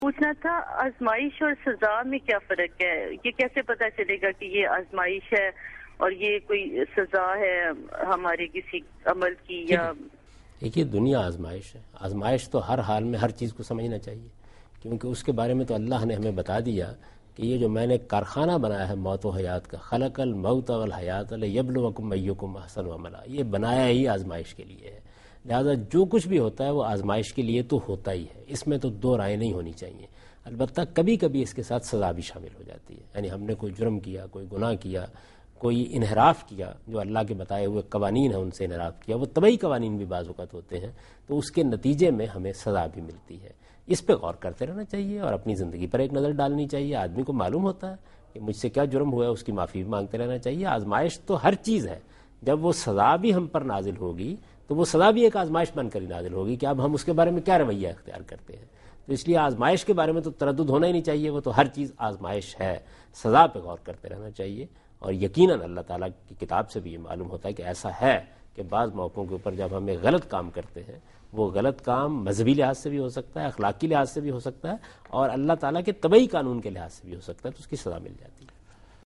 Category: TV Programs / Dunya News / Deen-o-Daanish / Questions_Answers /
دنیا نیوز کے پروگرام دین و دانش میں جاوید احمد غامدی ”آزمایش اور سزا “ سے متعلق ایک سوال کا جواب دے رہے ہیں